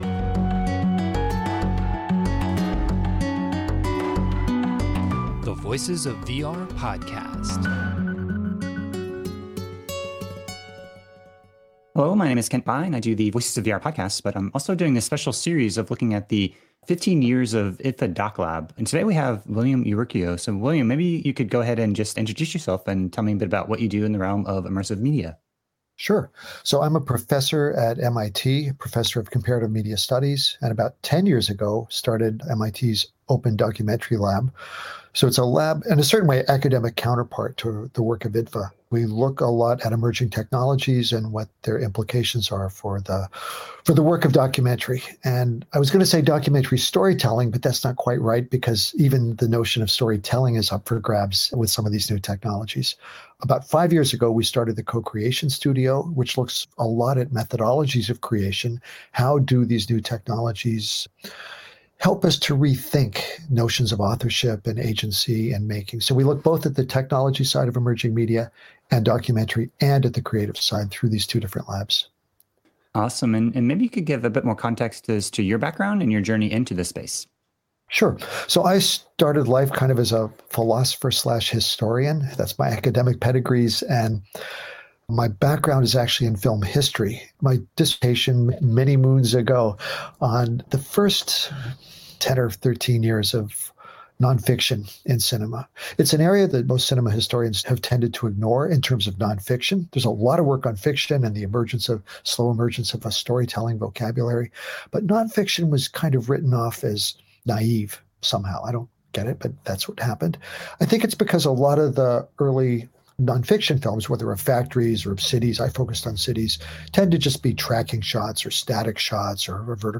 This interview was recorded on Tuesday, December 20th, 2021 as a part of a collaboration with IDFA’s DocLab to celebrate their 15th year anniversary.